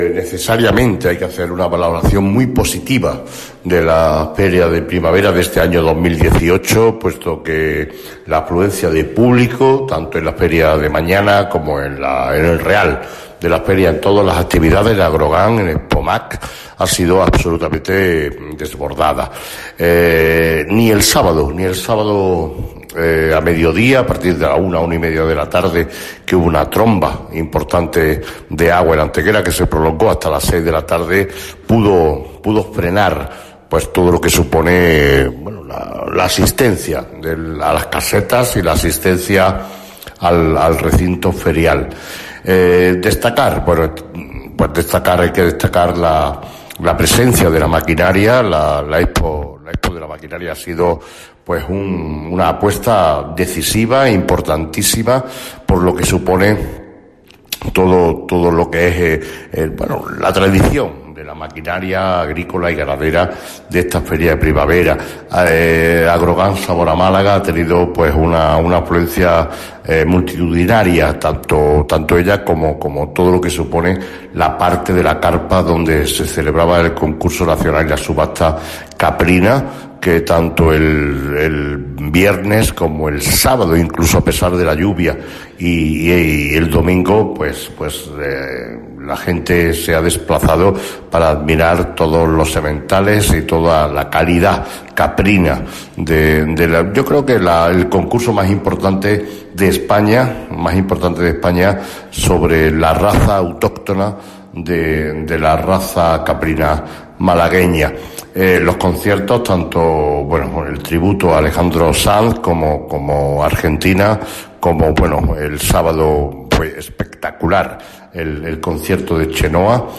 Generar Pdf lunes 4 de junio de 2018 Valoración del Alcalde de Antequera sobre la Feria de Primavera 2018 Generar Pdf AUDIO Corte de audio con valoración del alcalde de Antequera, Manolo Barón, sobre el desarrollo de la Feria de Primavera 2018. Cortes de voz M. Barón 2176.43 kb Formato: mp3